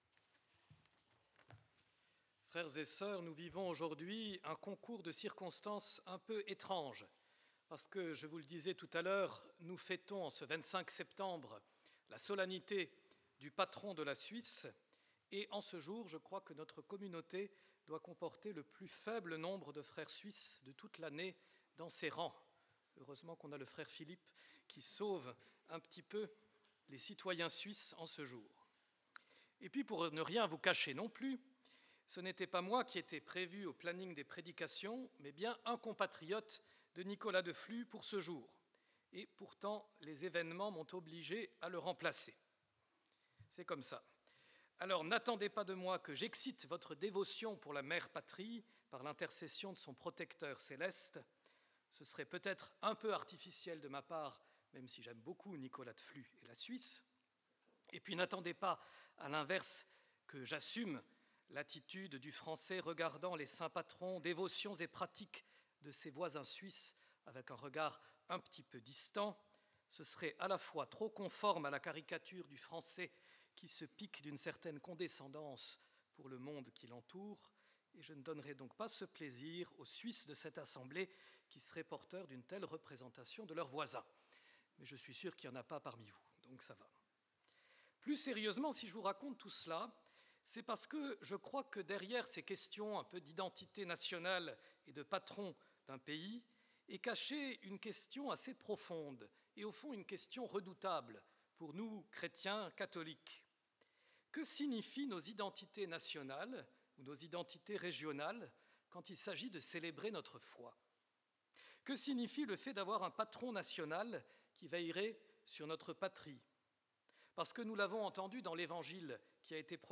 Enregistrement de l'homélie